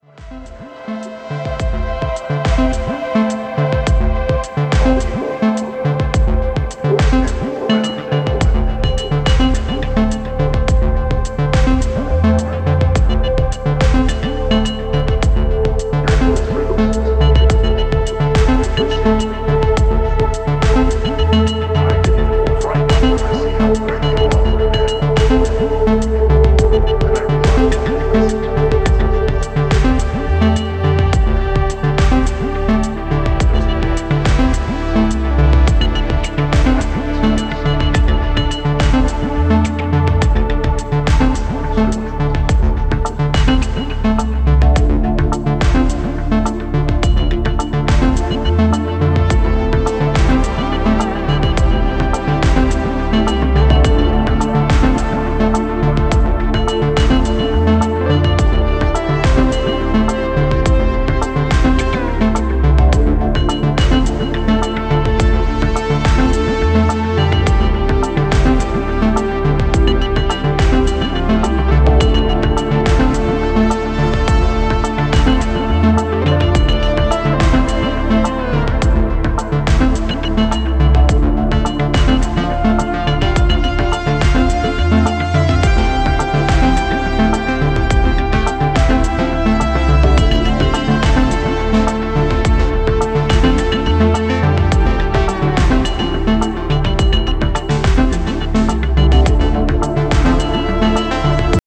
It comes with Dolby B noise reduction and two big VU meters in a thick aluminium front.
Below is a test recording made with SD-1000 and played back by it:
Marantz_SD1000_Test_Recording.mp3